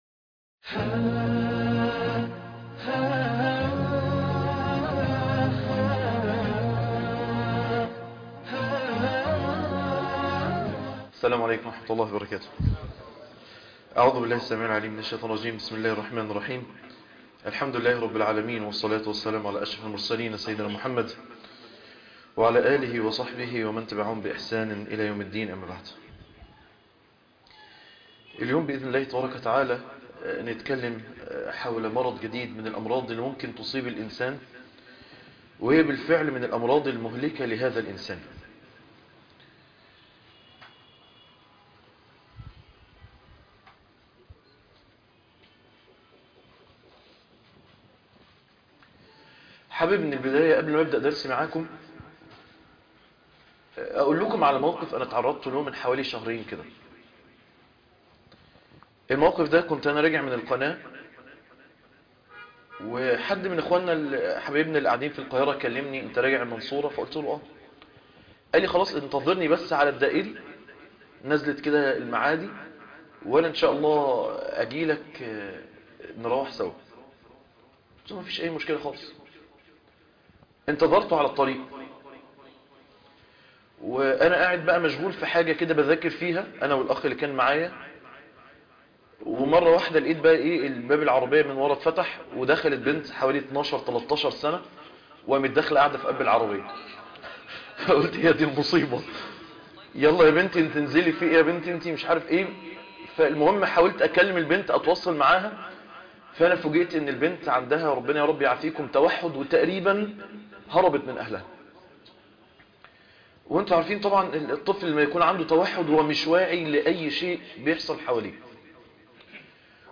المرض الثاني الغفلة...الدرس الخامس...الأمراض المهلكة